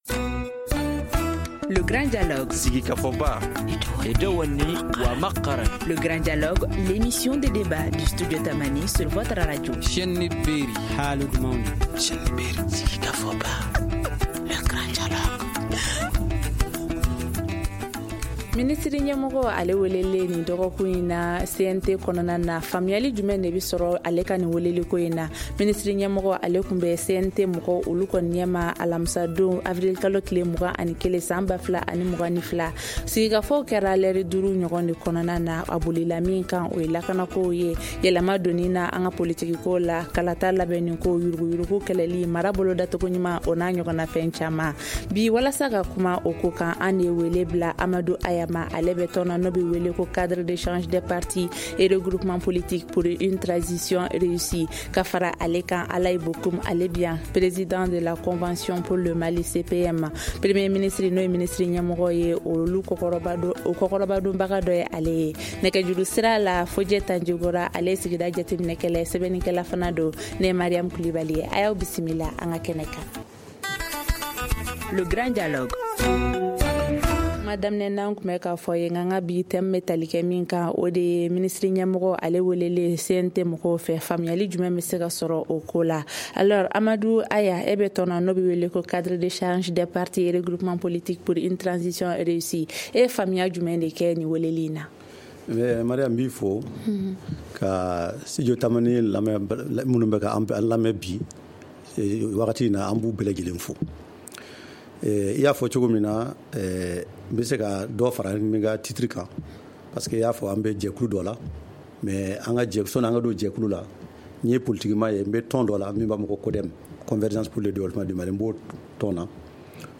Le débat en langue nationale Bamanankan
Pour en parler, nos invités sont :